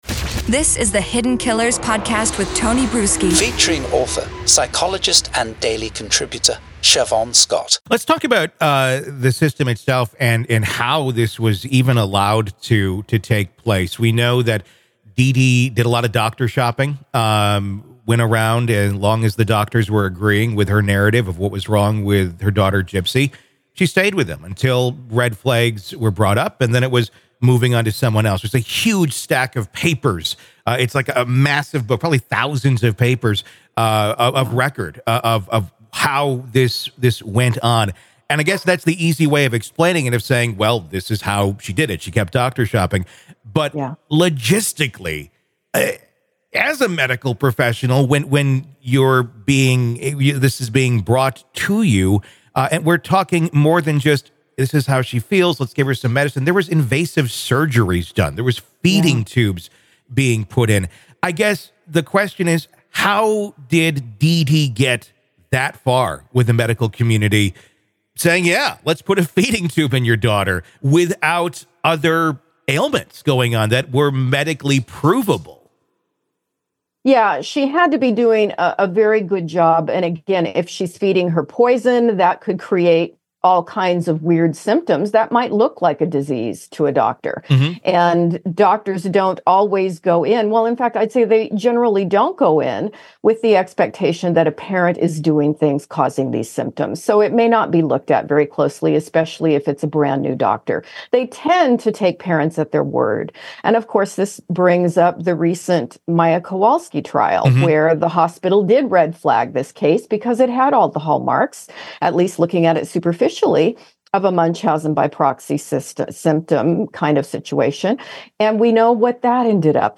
True Crime Today | Daily True Crime News & Interviews / How Did Gypsy Rose's Mother Develop Munchausen by Proxy?